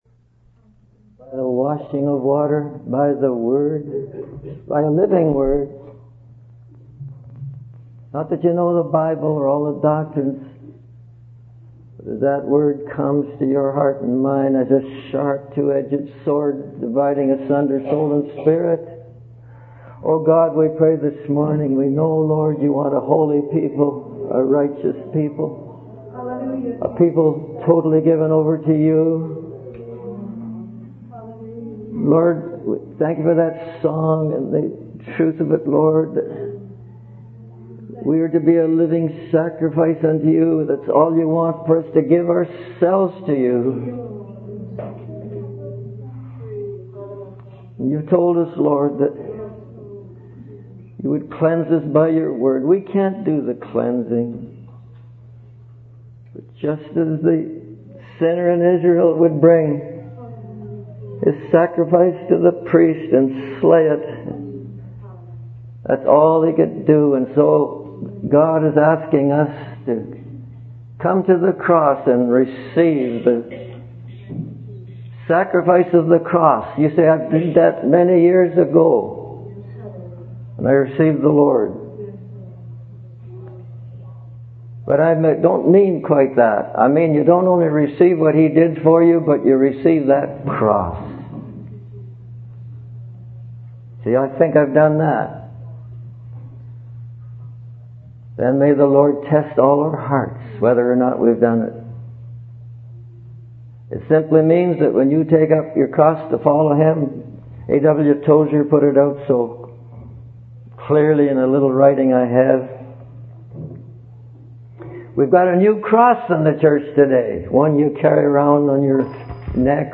In this sermon, the speaker emphasizes the importance of young people focusing on God's great mercy and demonstrating His keeping power. He urges them not to waste time experimenting with the world, as the Lord is going to move quickly.